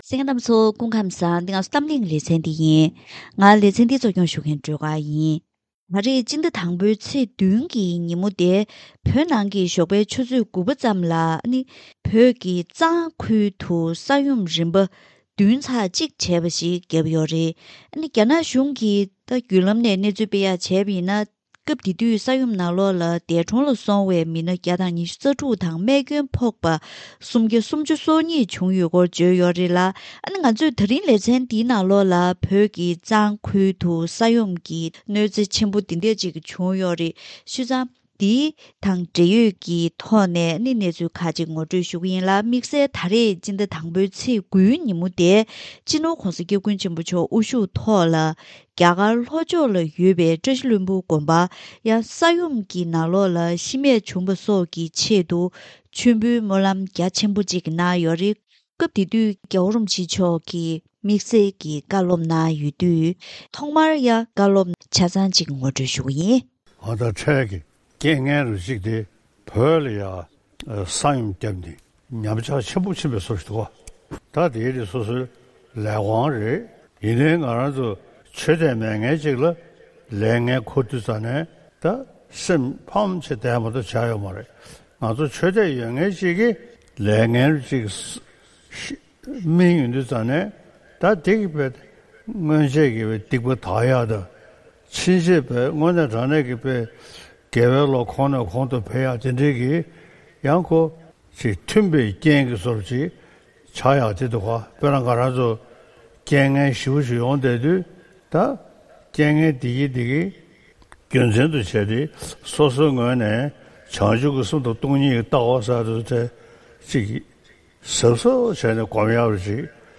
ད་རིང་གི་གཏམ་གླེང་ལེ་ཚན་ནང་བོད་ཀྱི་གཞིས་རྩེ་ཁུལ་གྱི་རྫོང་ཁག་ཅིག་ནང་ས་ཡོམ་གྱི་གནོད་འཚེ་ཆེན་པོ་བྱུང་ཡོད་པ་དང་། རང་བྱུང་གནོད་འཚེ་འདིའི་ཐོག་བོད་ཀྱི་ས་ཕྱོགས་འདྲ་མིན་ནས་བོད་མི་མང་ཚོགས་ཀྱིས་རང་འགུལ་ངང་ཞལ་འདེབས་བསྡུ་རུབ་གནང་སྟེ་མྱུར་སྐྱོབ་ལས་དོན་ནང་མཉམ་ཞུགས་གནང་ཡོད་པ་དང་། བཙན་བྱོལ་ནང་མཚོན་ན་༧གོང་ས་༧སྐྱབས་མགོན་ཆེན་པོ་མཆོག་དབུ་བཞུགས་ཐོག་མཆོད་འབུལ་སྨོན་ལམ་རྒྱ་ཆེ་གནང་ཡོད་པ་བཅས་ཀྱི་ཐོག་ནས་ས་ཡོམ་གྱི་གོད་ཆག་དང་འབྲེལ་བའི་ཐོག་ལ་བགྲོ་གླེང་ཞུས་པ་ཞིག་གསན་རོགས་གནང་།